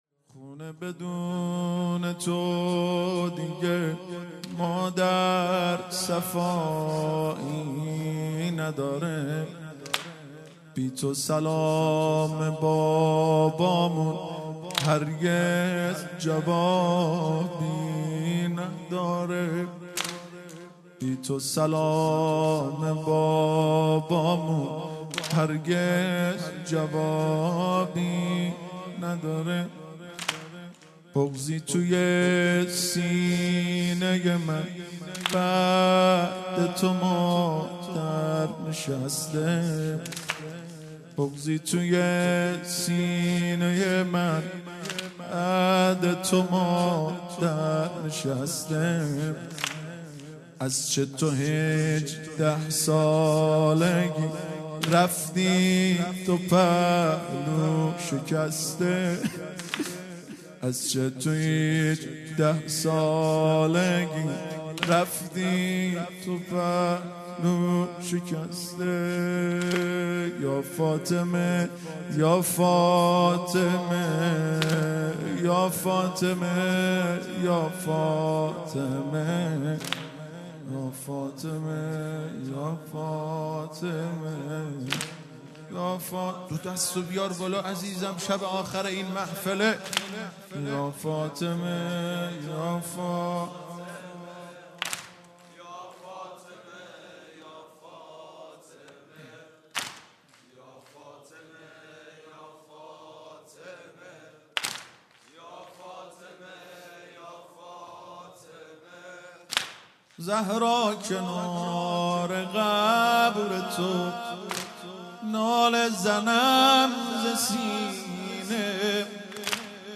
شور
شهادت حضرت زهرا (س) | ۲۵ بهمن ۱۳۹۵